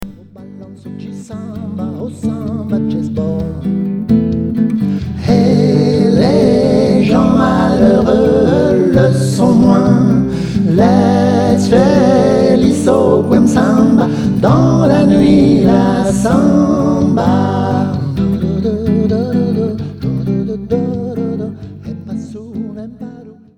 version acoustique